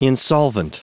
Prononciation du mot insolvent en anglais (fichier audio)
Prononciation du mot : insolvent